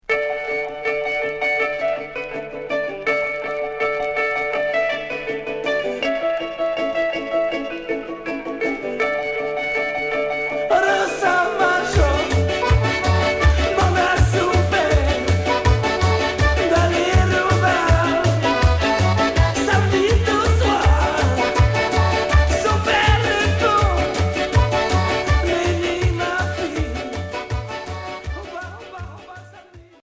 Folk Rock